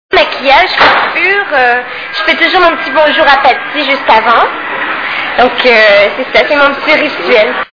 STARMANIA...Interview
( Casino de Paris, Hall d'entrée, 06/02/2000 )